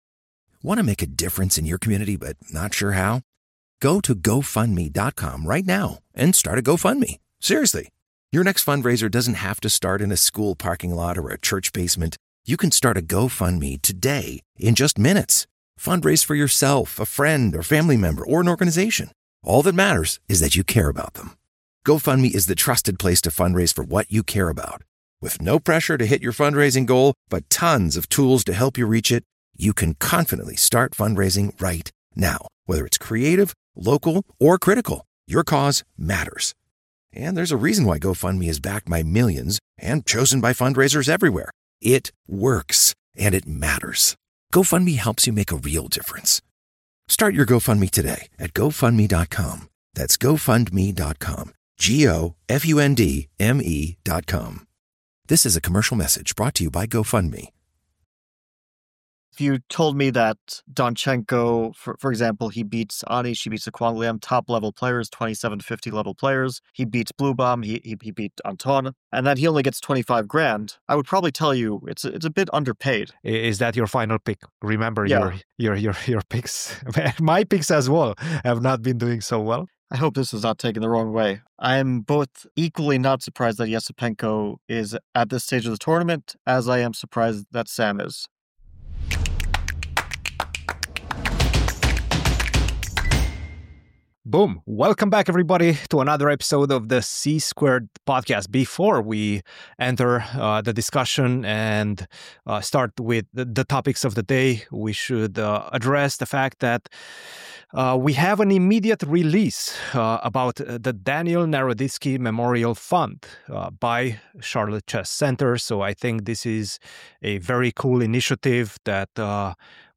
The C-Squared Podcast is an in depth weekly discussion about the chess world with your hosts